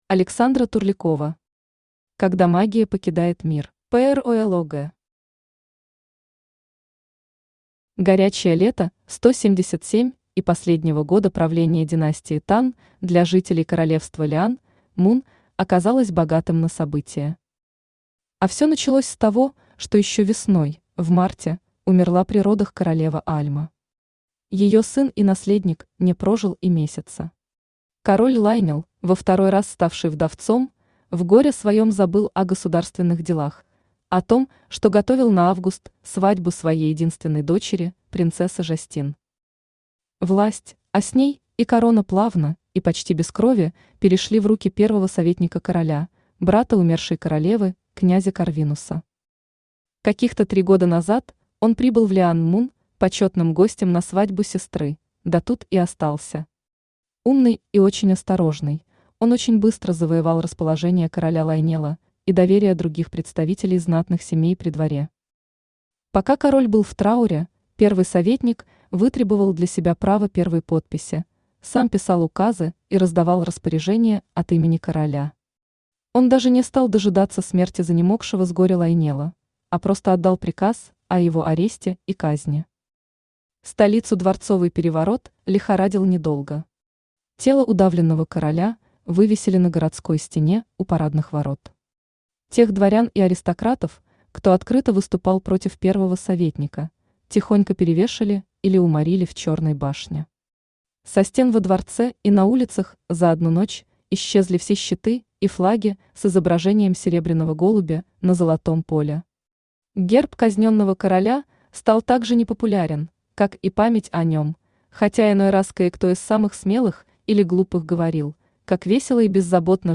Аудиокнига Когда магия покидает мир | Библиотека аудиокниг
Aудиокнига Когда магия покидает мир Автор Александра Турлякова Читает аудиокнигу Авточтец ЛитРес.